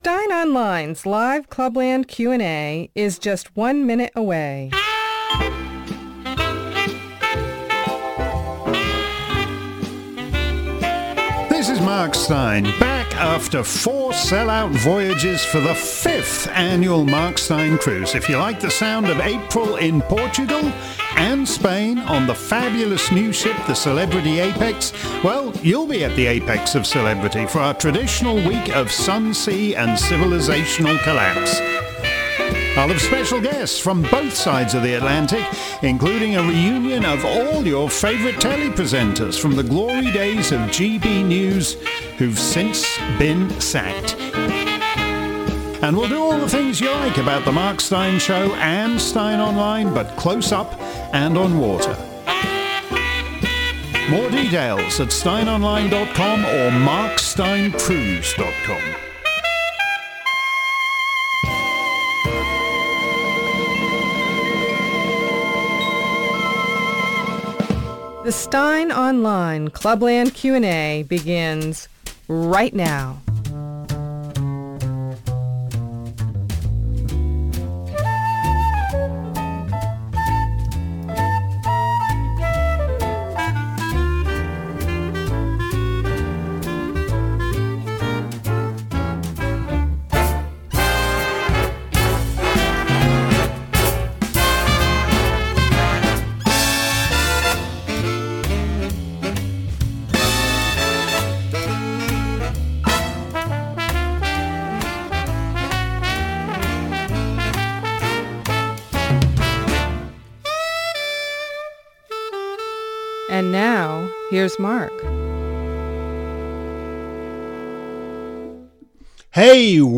If you missed today's edition of Mark's Clubland Q&A live around the planet, here's the action replay. Steyn was back at the microphone, fielding questions on Trump's new team ...and the same old-same old over in the Senate.